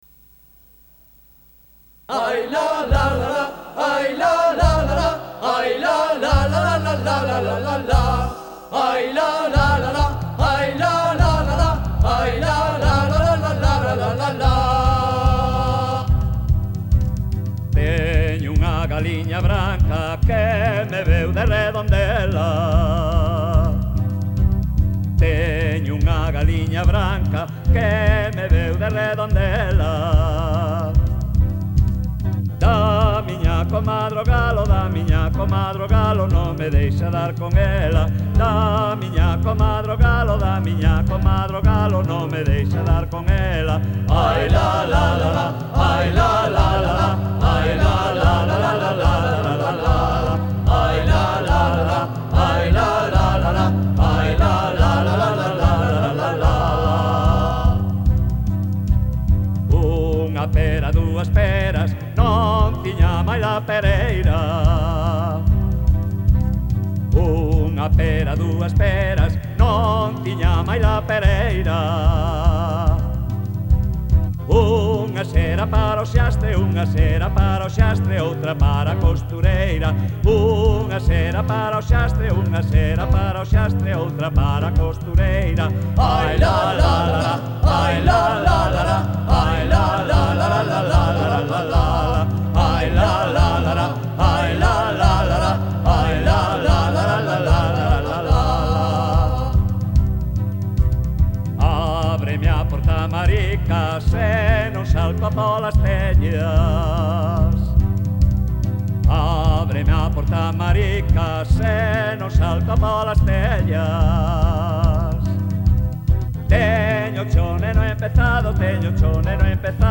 Música:Popular